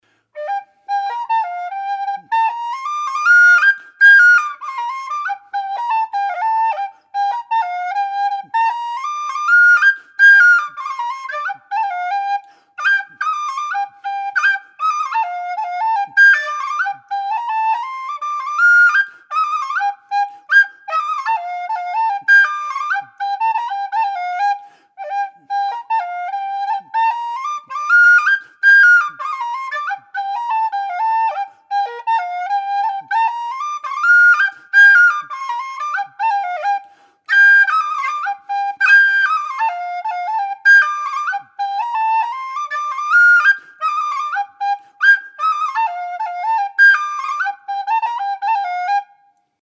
Bb whistle - 145 GBP
made out of thin-walled aluminium tubing with 15.7mm bore